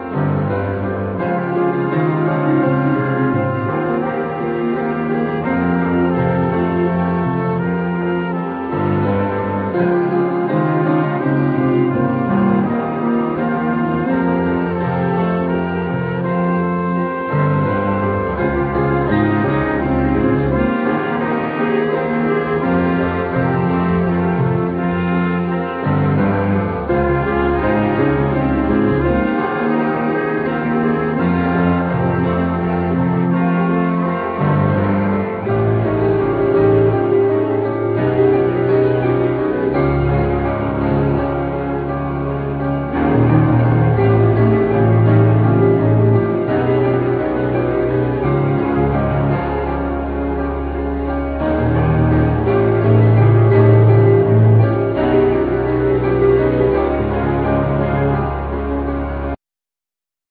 Piano,Vibes,Linen sheet
Cello
Violin